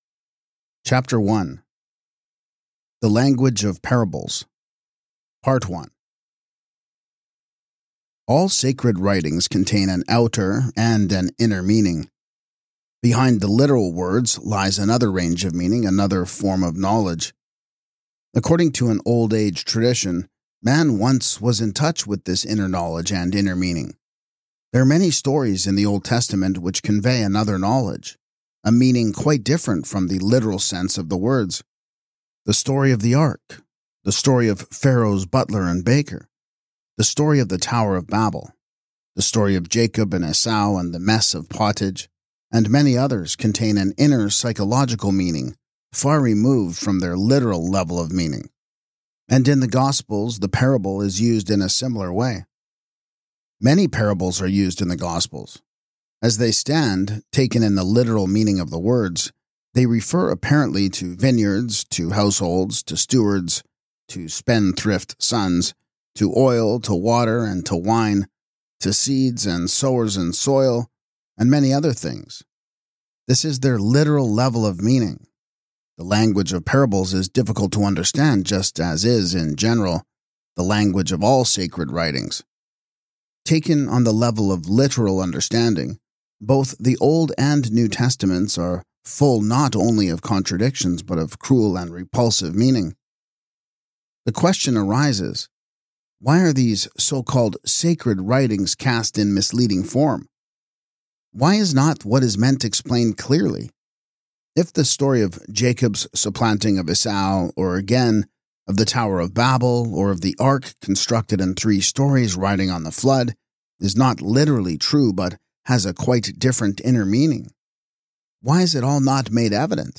Perfect for seekers of deeper spiritual understanding, this audiobook invites listeners to contemplate the transformative power of Christ’s teachings.